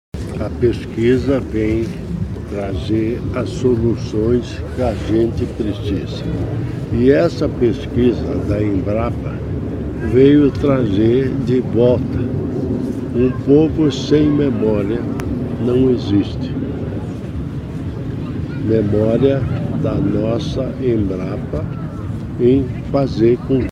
Sonora do vice-governador Darci Piana sobre o plantio de clone de araucária de 700 anos | Governo do Estado do Paraná